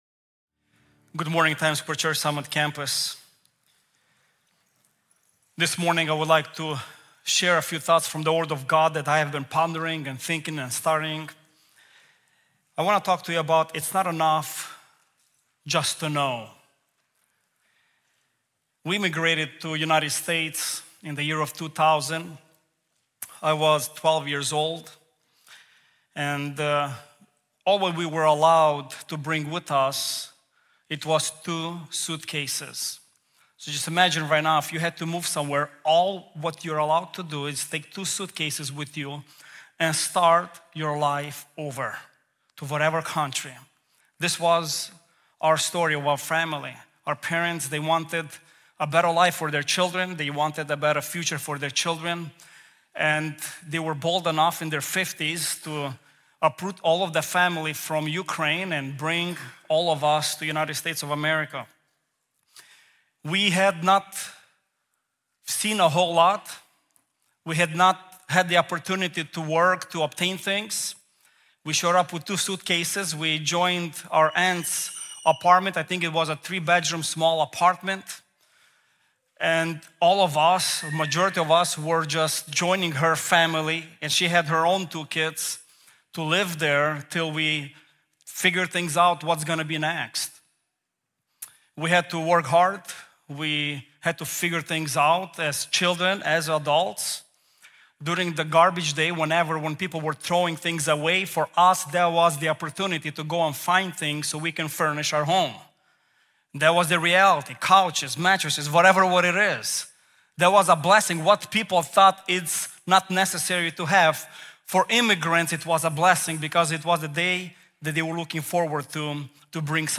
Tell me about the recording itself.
Our messages are recorded at Times Square Church in New York City.